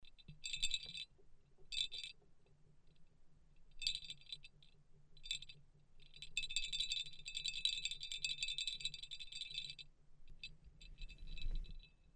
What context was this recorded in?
Sound recordings of original pellet bells and bells from the archaeological collection of the Slovak National Museum Bratislava, SK.